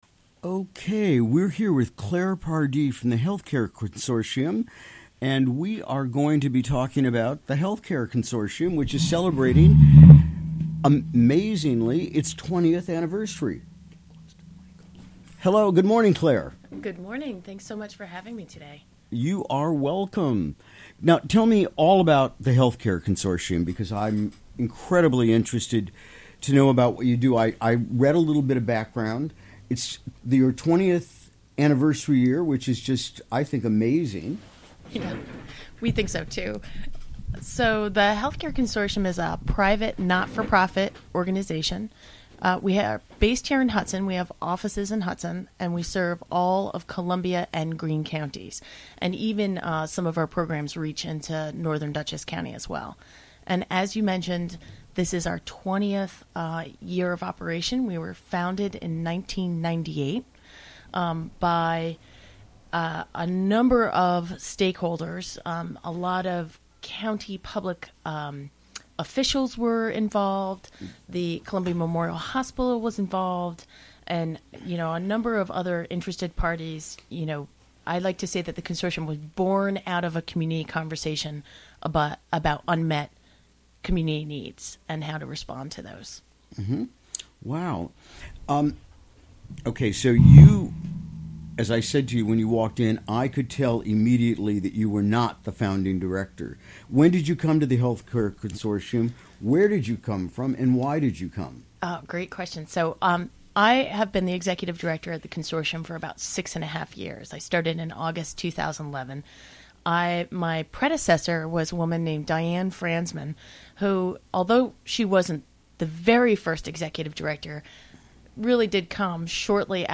Recorded live during the WGXC Morning Show on Wednesday, March 28, 2018.